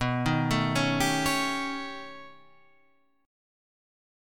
B Augmented 9th